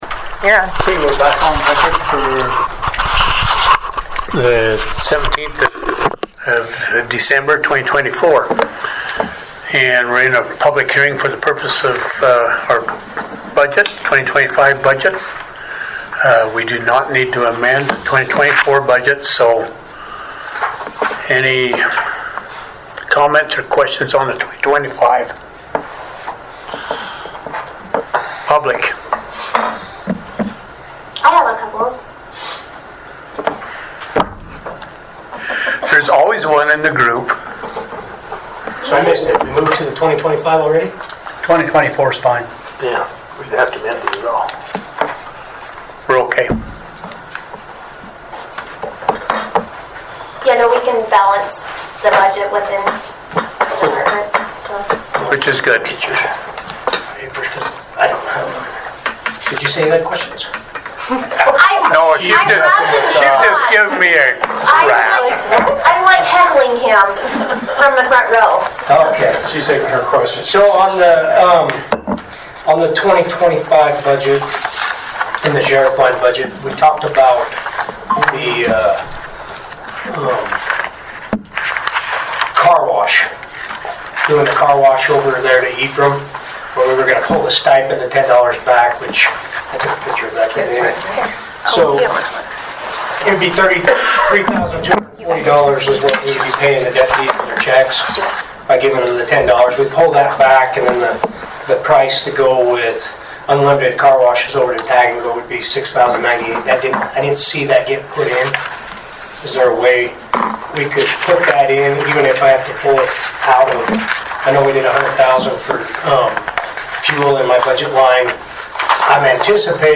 Notice is hereby given that Sanpete County will hold a public hearing on Tuesday December 17, 2024 at the Sanpete County Courthouse, Commission Room, Manti, Utah for the purpose of amending the Budget for 2024 and holding a public hearing for the Sanpete County 2025 budget at 6 p.m.